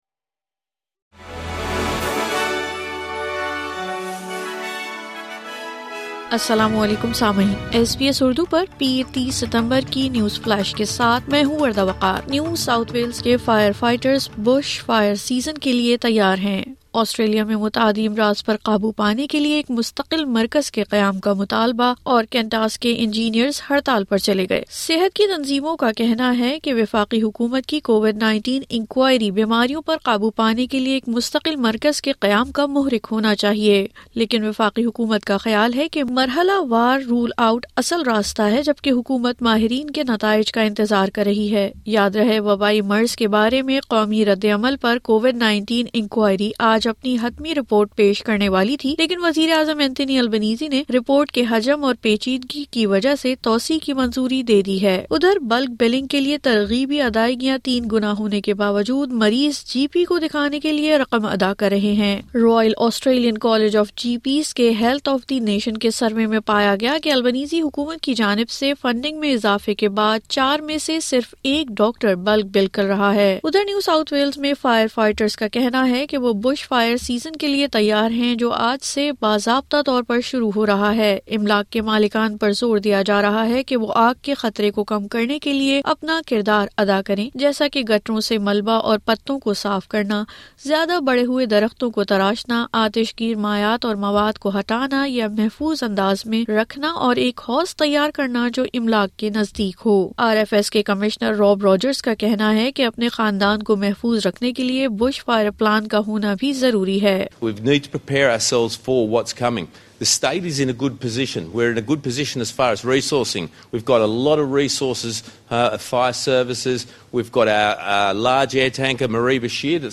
کنٹاس کے انجینئرز ہڑتال پر , آسٹریلیا میں متعدی امراض پر قابو پانے کے لئے ایک مستقل مرکز کے قیام کا مطالبہ۔ مزید خبروں کے لئے سنئے اردو نیوز فلیش